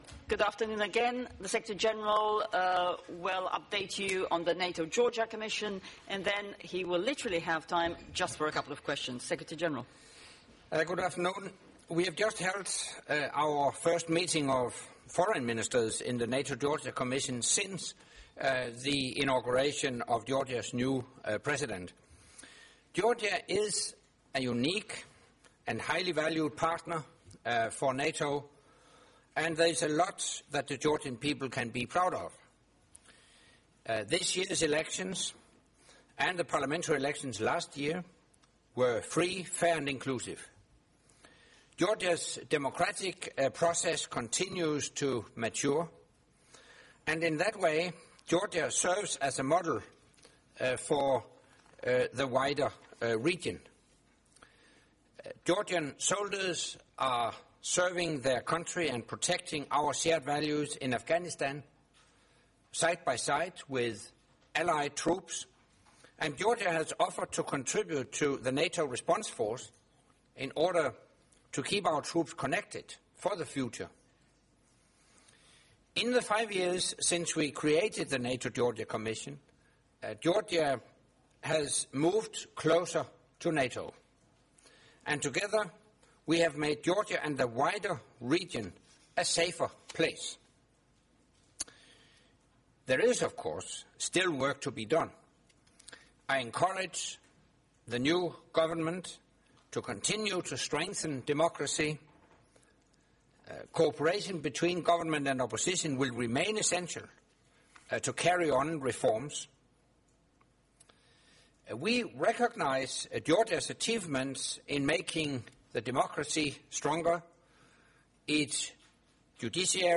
Press conference by NATO Secretary General Anders Fogh Rasmussen following the meeting of the NATO-Georgia Commission at the level of Foreign Affairs Ministers